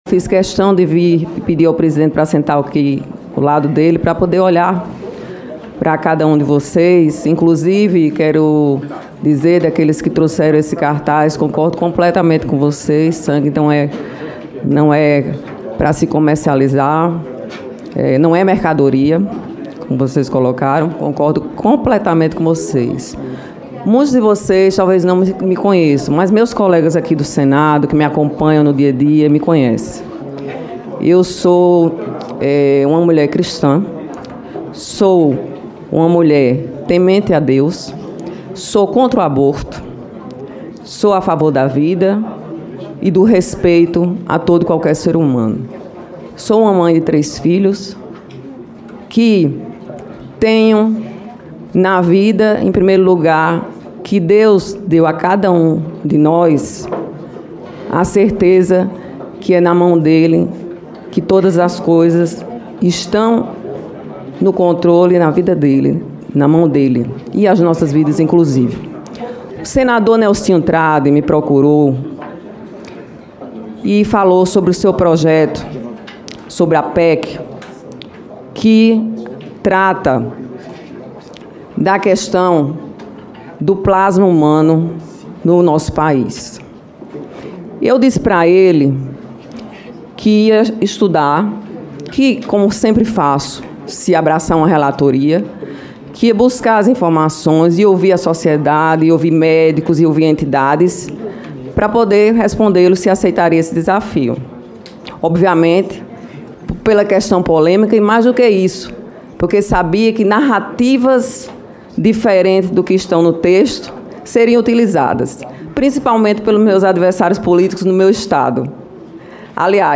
Declaração de Daniella